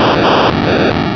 Cri de Voltorbe dans Pokémon Rubis et Saphir.